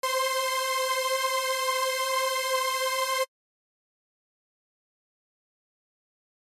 This results in a nice thick slightly evolving texture.
• 7th Order (7 voices)